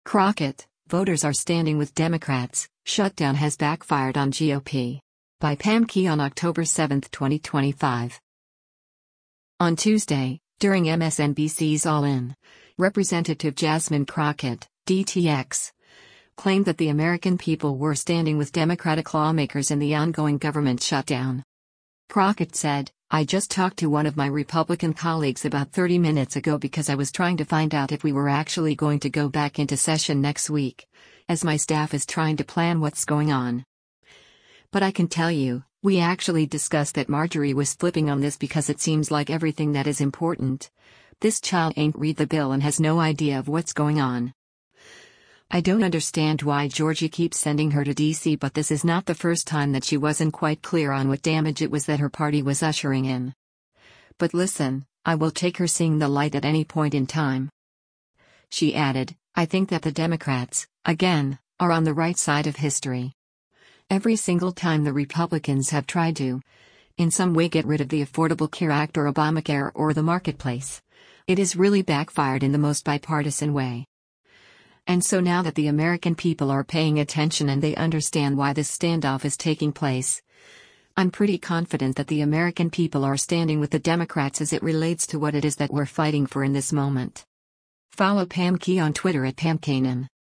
On Tuesday, during MSNBC’s “All In,” Rep. Jasmine Crockett (D-TX) claimed that the American people were standing with Democratic lawmakers in the ongoing government shutdown.